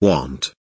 want kelimesinin anlamı, resimli anlatımı ve sesli okunuşu